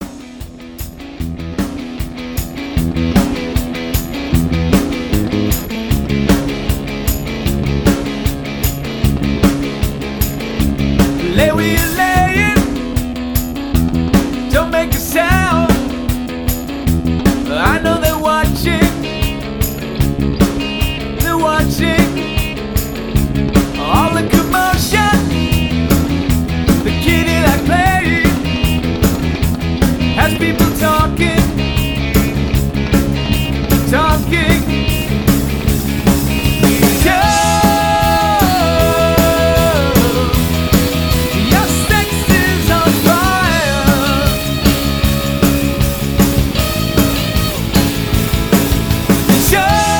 • Versatile 3- to 9-piece party band